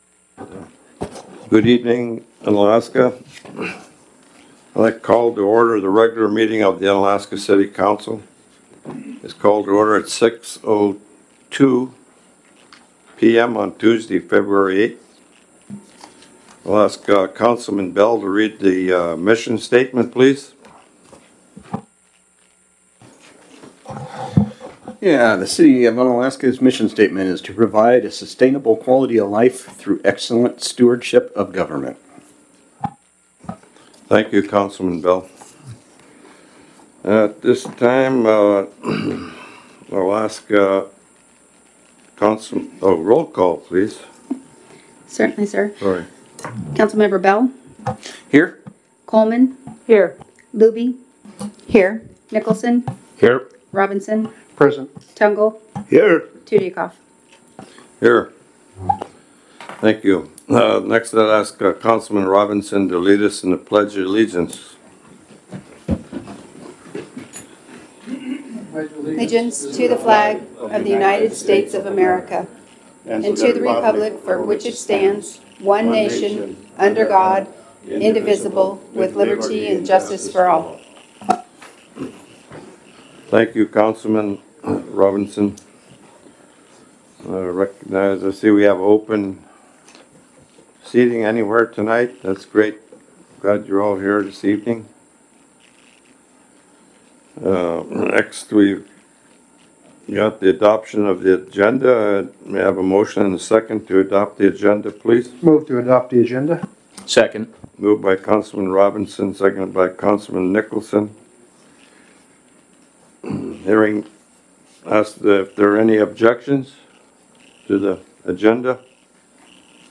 City Council Meeting - February 8, 2022 | City of Unalaska - International Port of Dutch Harbor